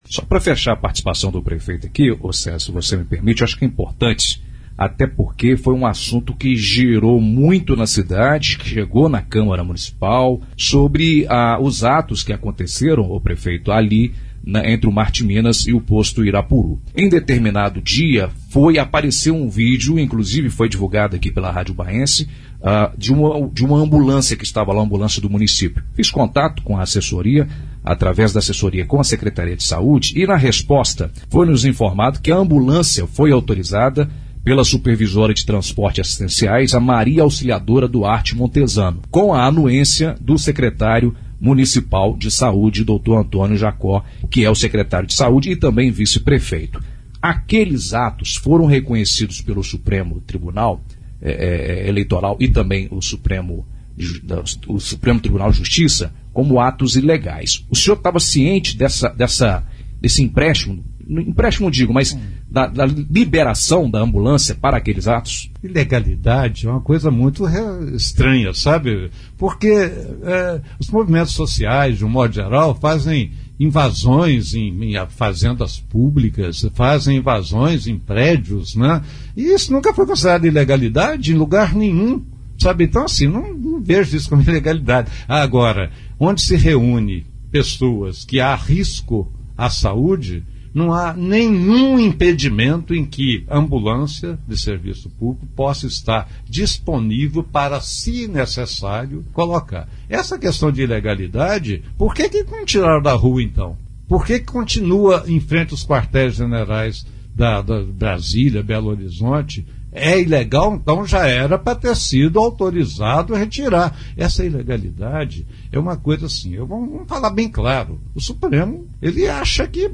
Parte da entrevista com Prefeito Edson, exibida na Rádio Ubaense FM  104,1.